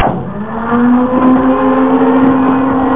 Amiga 8-bit Sampled Voice
Turntable.mp3